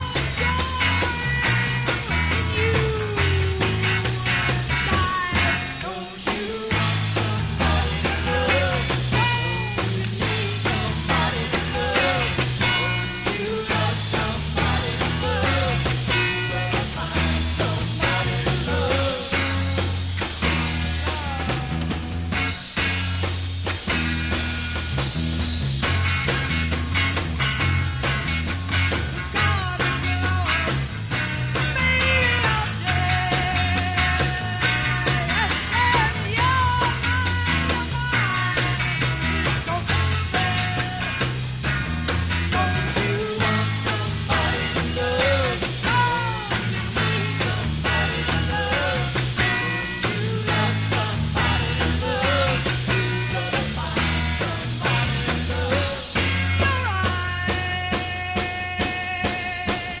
Рок
"кислотная" гитара и глубокий, потусторонний вокал